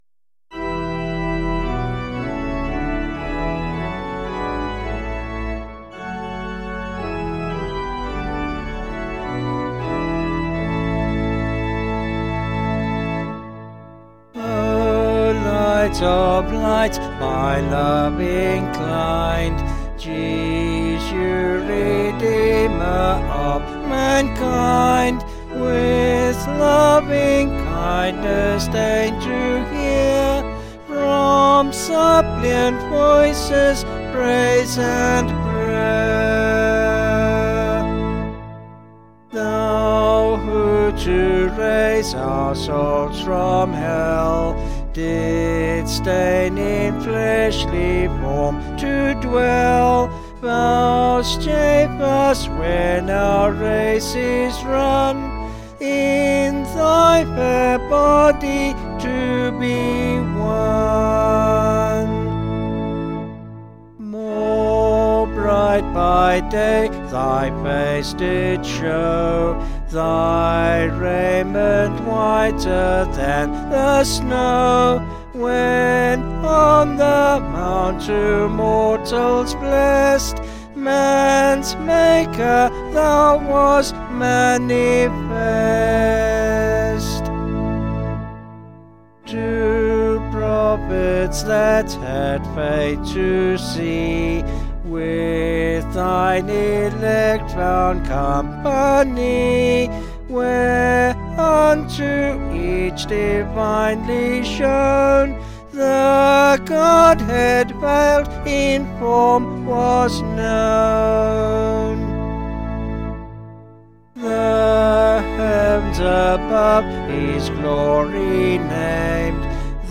Vocals and Organ   706.6kb Sung Lyrics